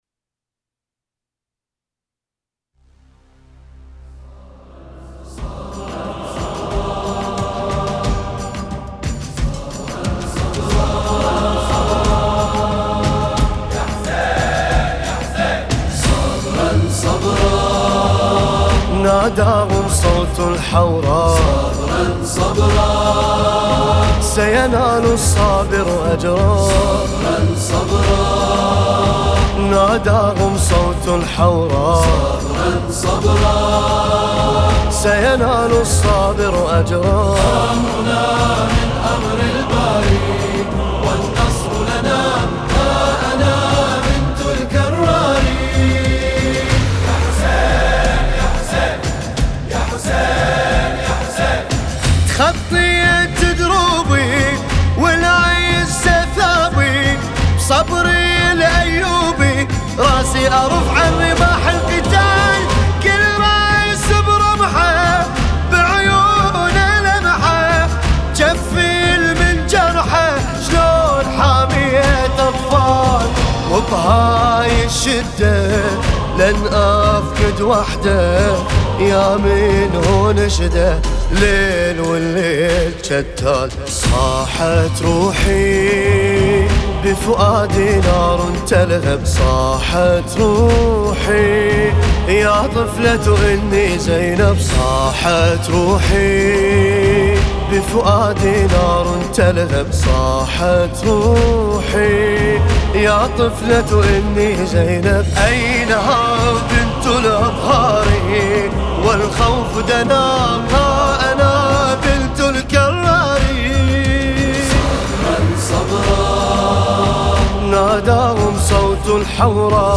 لطمية